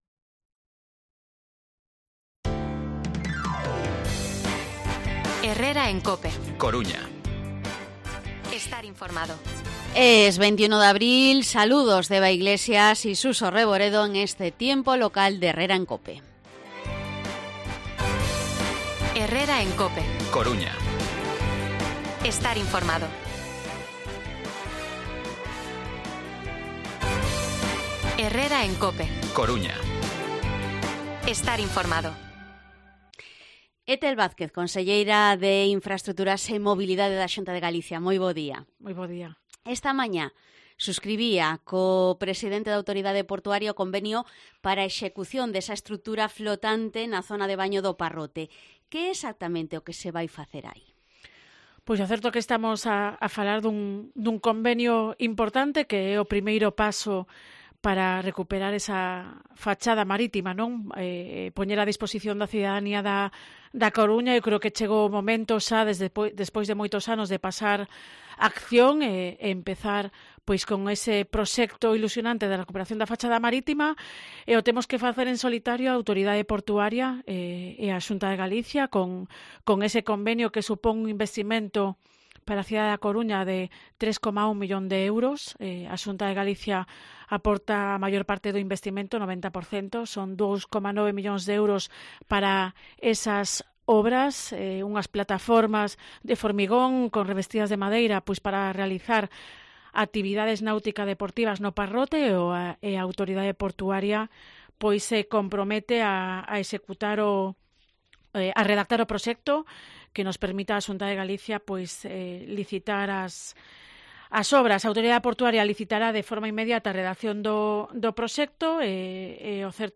AUDIO: Entrevista conselleira de infraestructuras e mobilidade, Ethel Vázquez